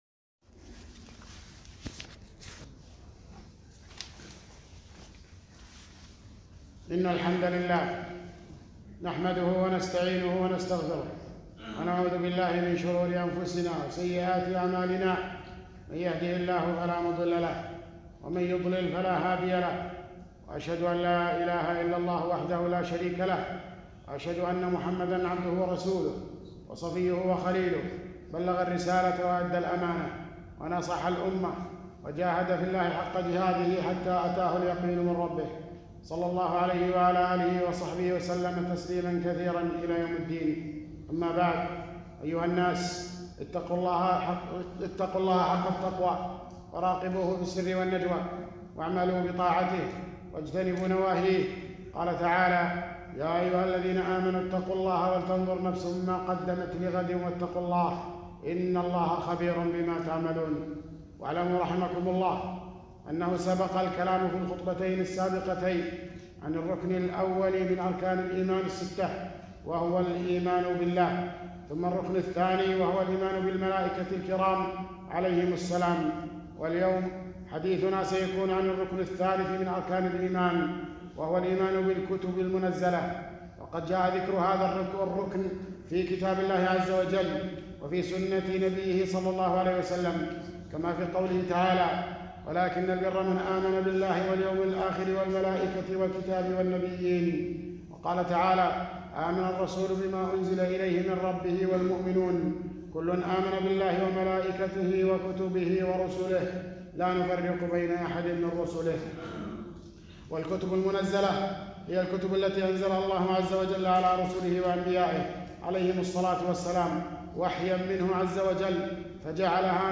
٣- خطبة - الإيمان بالكتب المنزلة